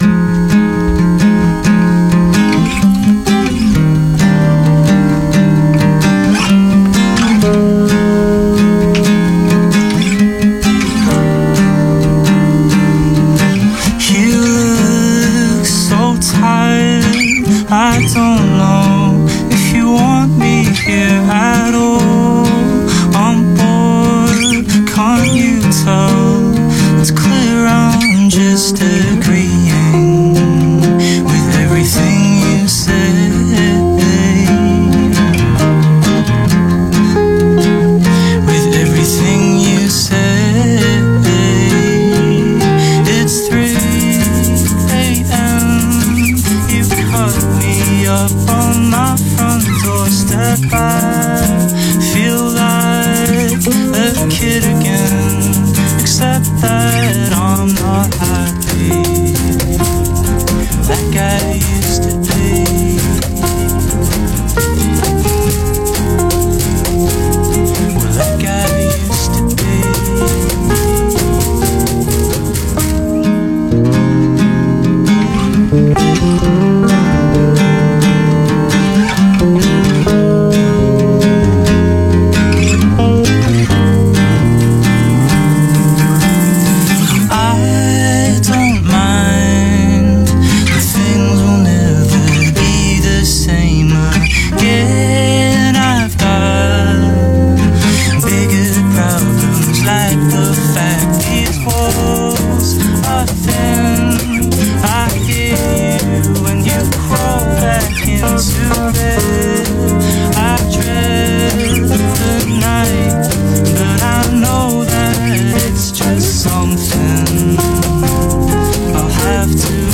music to breathe deep and exhale to.